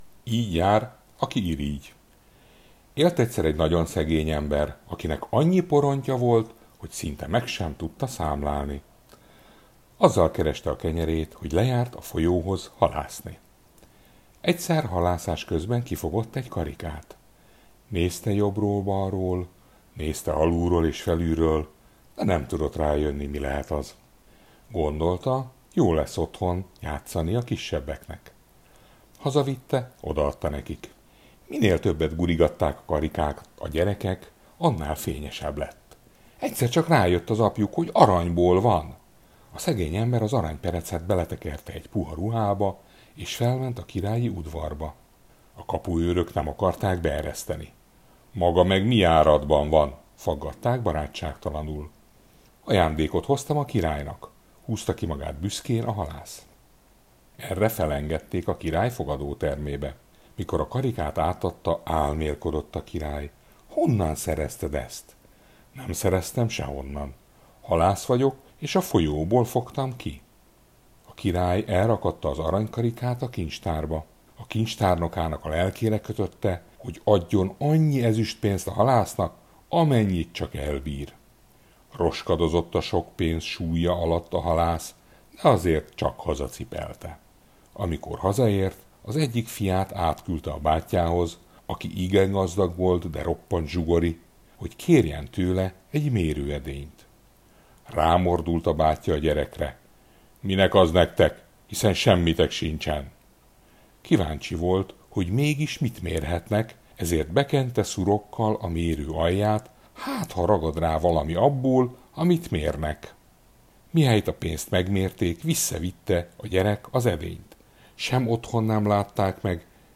Mese gyerekeknek